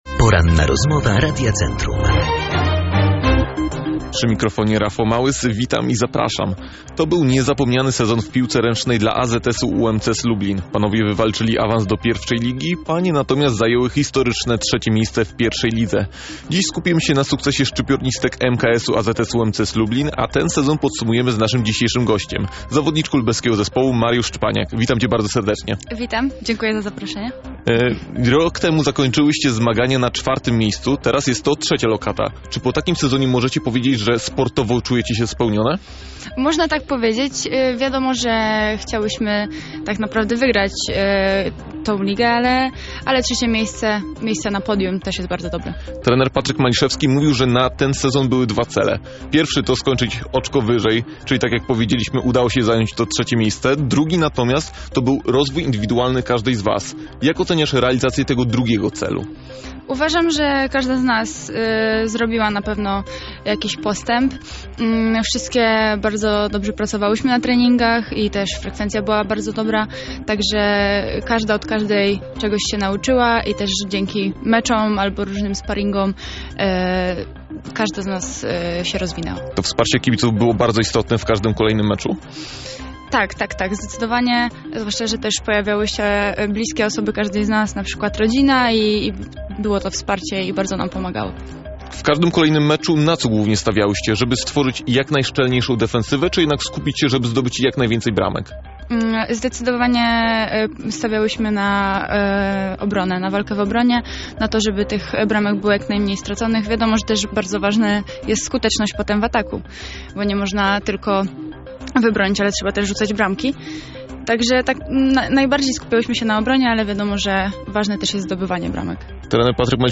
Rozmowa-po-edycji-2.mp3